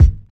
• 00s Puffy Kick Drum One Shot G Key 344.wav
Royality free bass drum one shot tuned to the G note. Loudest frequency: 102Hz
00s-puffy-kick-drum-one-shot-g-key-344-ptY.wav